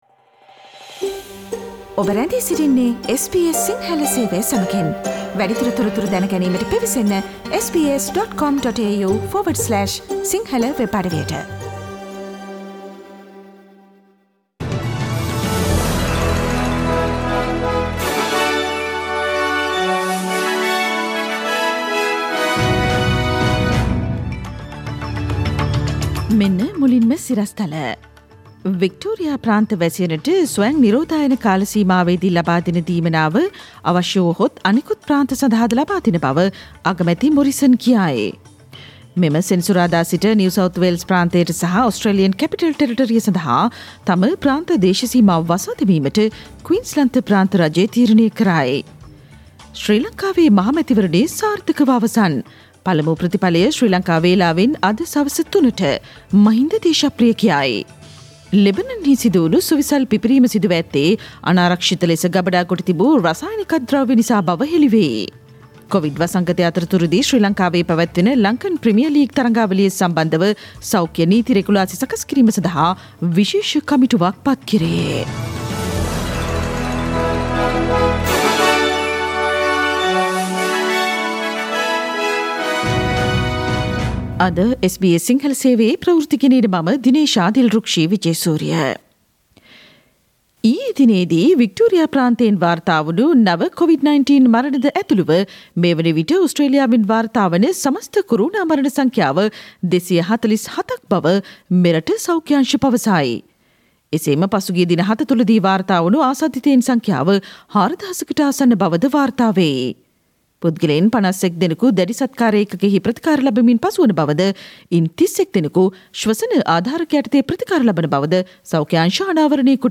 Daily News bulletin of SBS Sinhala Service: Thursday 6 August 2020
Today’s news bulletin of SBS Sinhala radio – Thursday 6 August 2020.